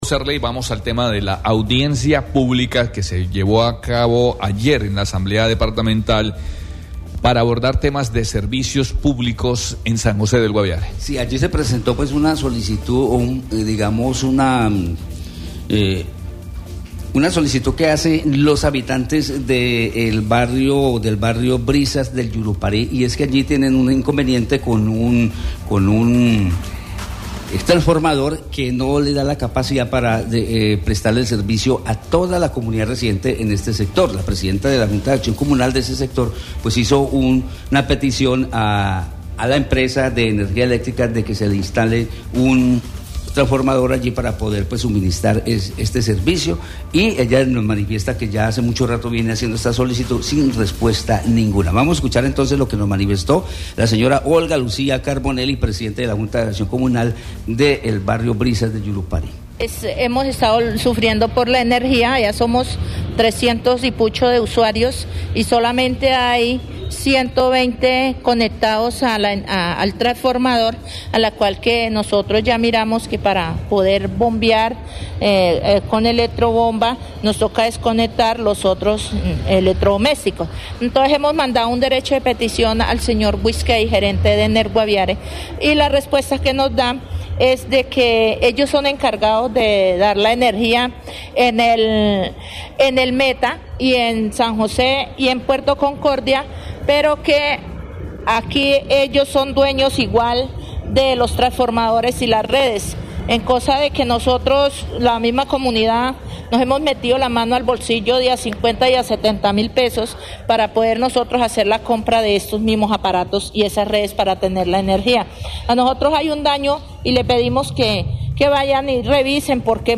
Alexander Cifuentes primer Vicepresidente de la Asamblea del Guaviare, expresó en Marandua Noticias que se cumplió con una jornada que se extendió hasta las 9:30 p.m. en la que se pudo generar espacios para que la comunidad diera a conocer a los gerentes de las empresas de servicios públicos de San José del Guaviare, sus sugerencias, reclamos e inquietudes sobre los servicios que reciben los usuarios.